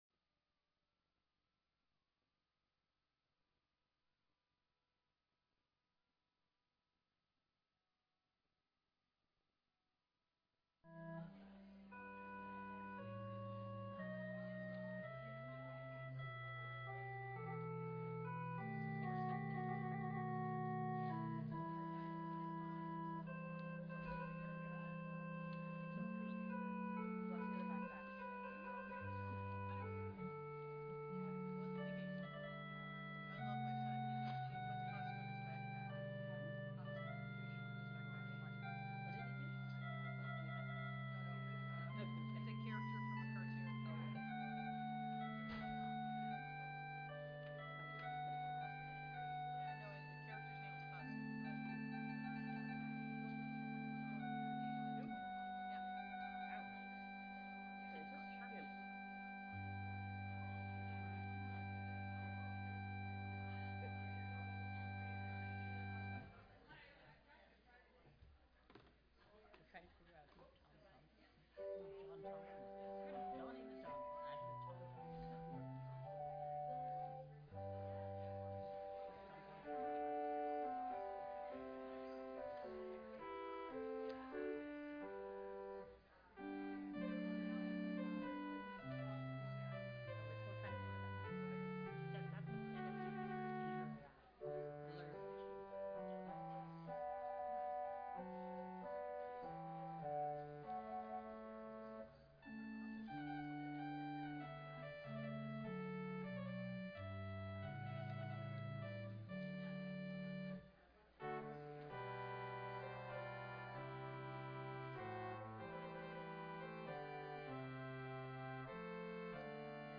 Passage: Psalm 128 Verse 1 & 2, 1 Corinthians 12:12-27, Romans12:6-8 Service Type: Standard « Sunday Aug. 25th